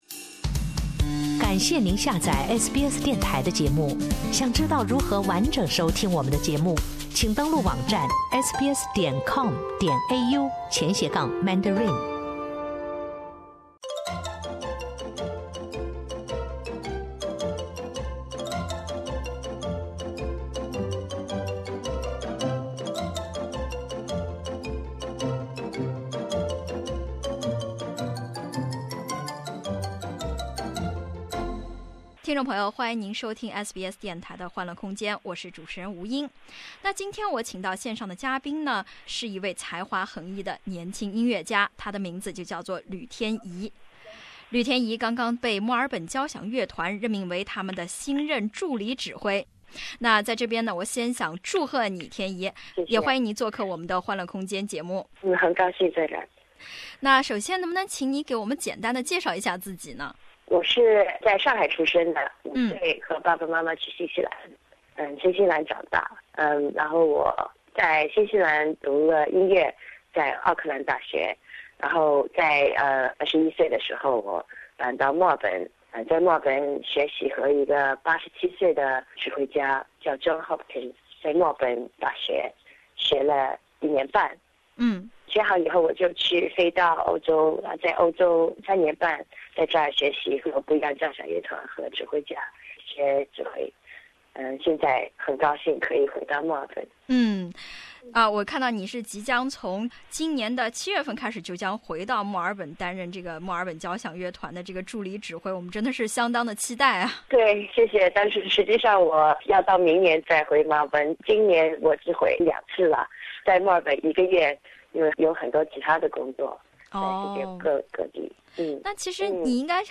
听到的全部是欢愉和希望